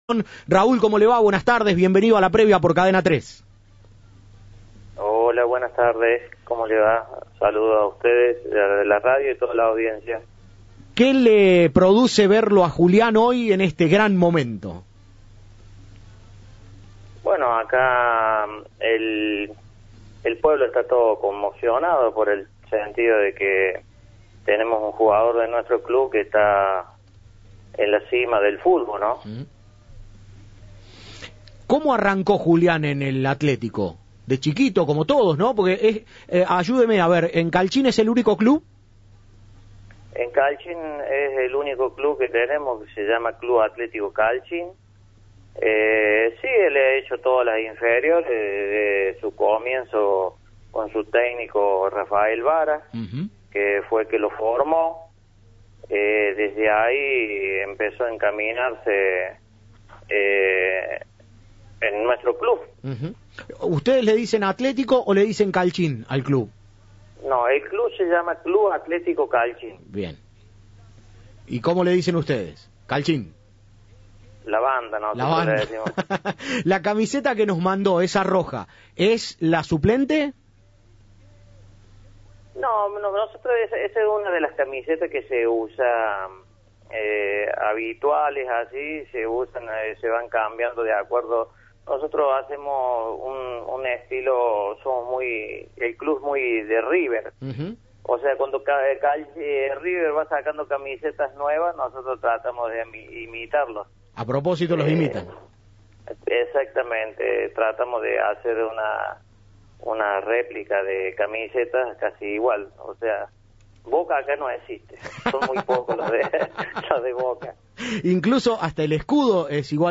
Entrevista de La Previa.